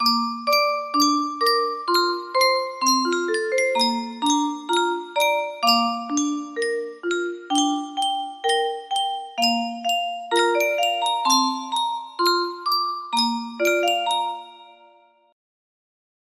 Yunsheng Music Box - Hush Little Baby 1014 music box melody
Full range 60